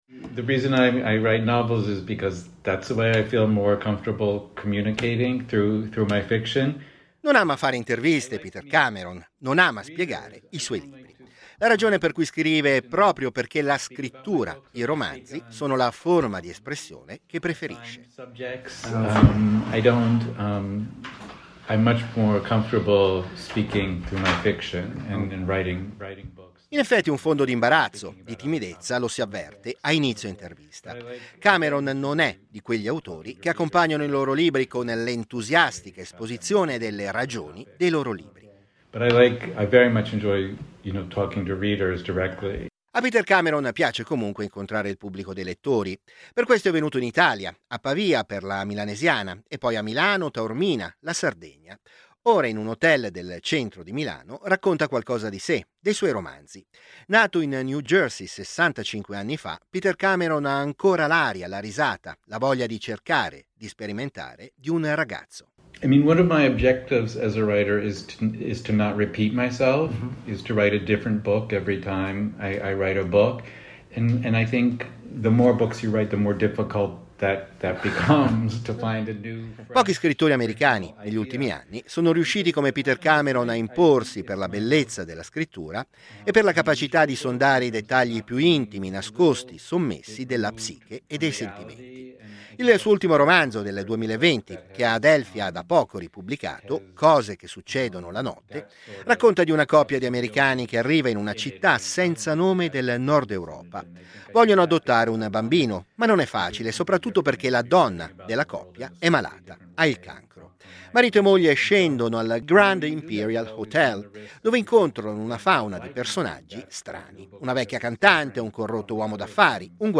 In questi giorni è in Italia e lo ha intervistato per noi